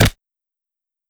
mixkit-weak-fast-blow-2145.wav